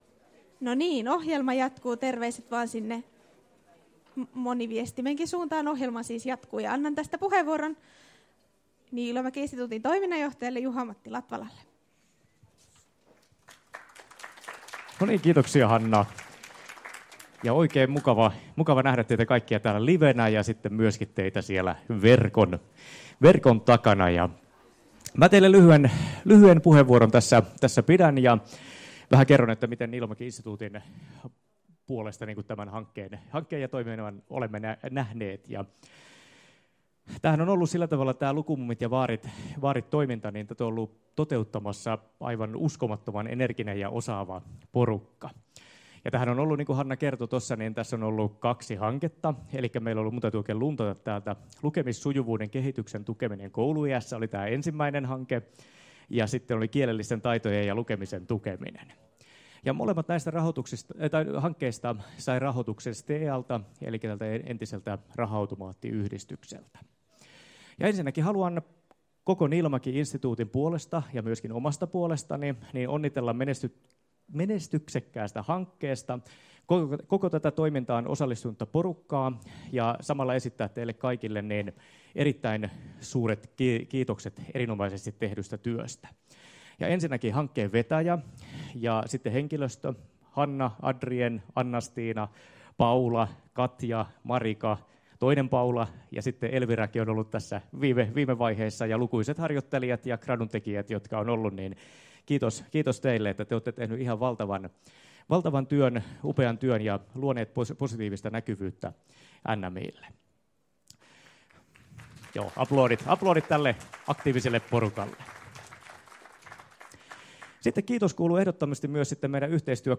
Päätösseminaari Maahanmuuttajien kielellisten taitojen ja lukemisen tukeminen